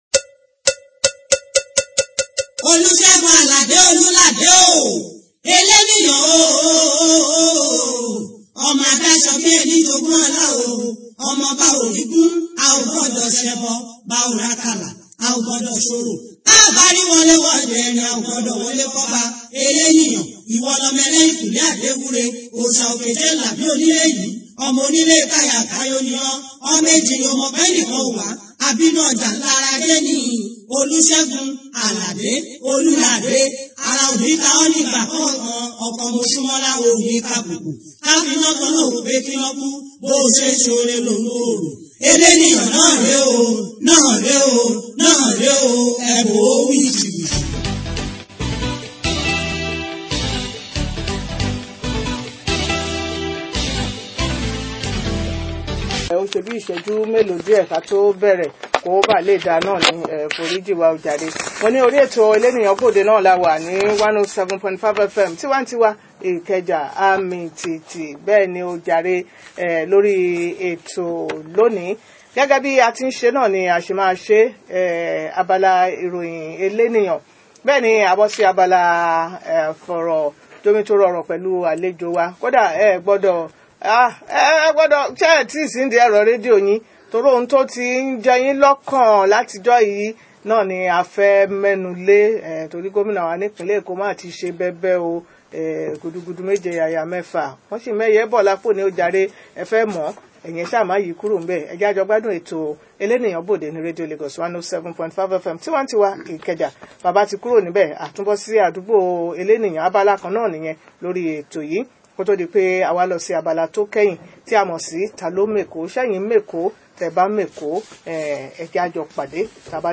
Guest: Hon. Victor Akande – Ojo Constituency 1